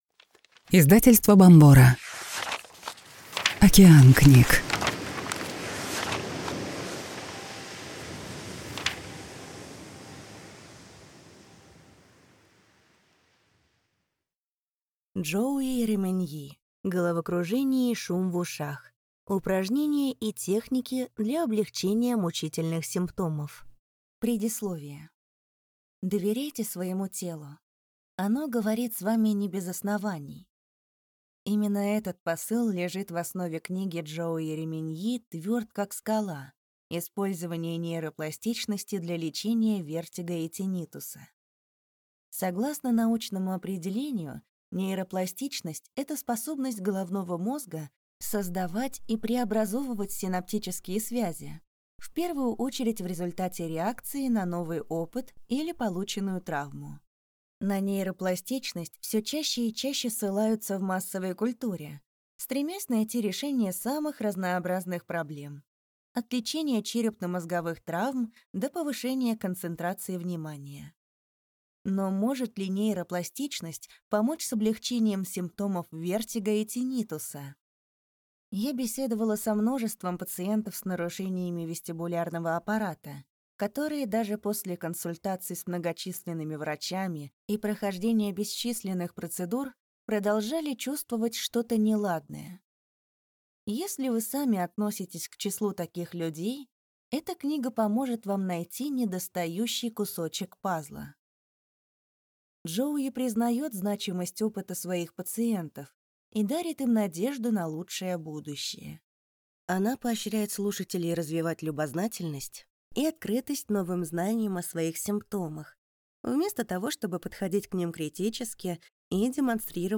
Аудиокнига Головокружение и шум в ушах. Упражнения и техники для облегчения мучительных симптомов | Библиотека аудиокниг